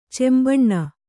♪ cembaṇṇa